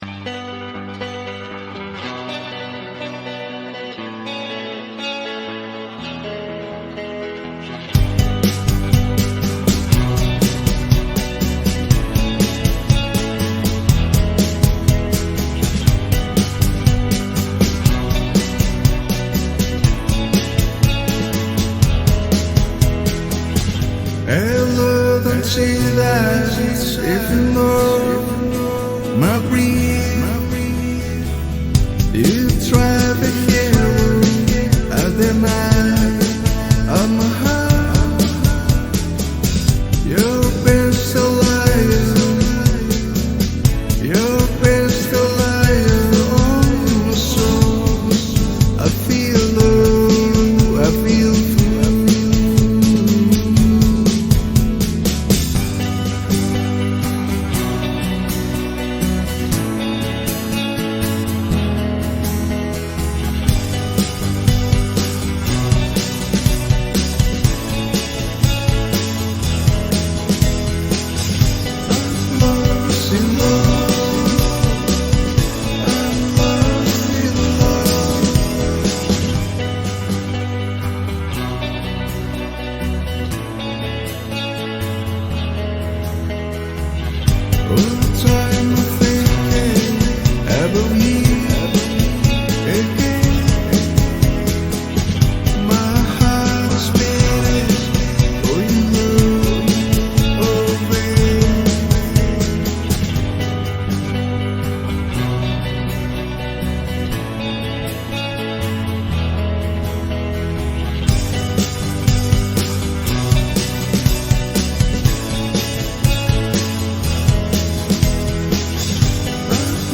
guitarra principal y segunda voz
la música alternativa de los años 90 y 2000